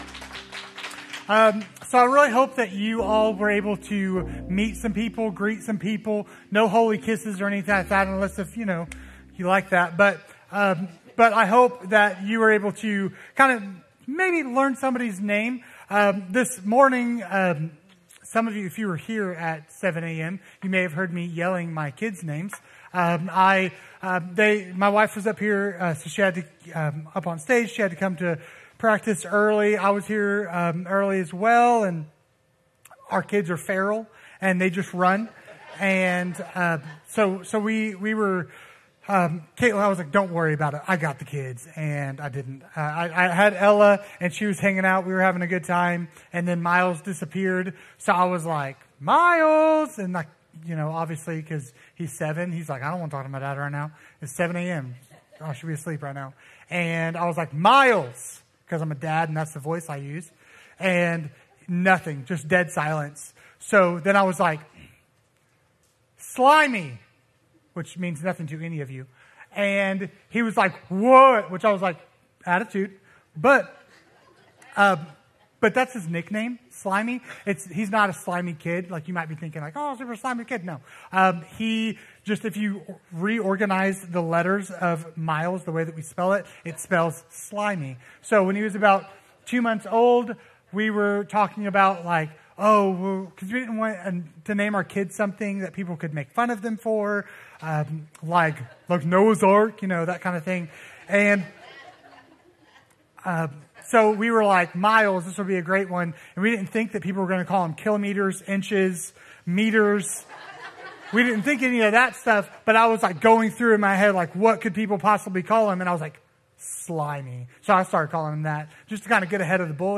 A message from the series "He Shall Be Called."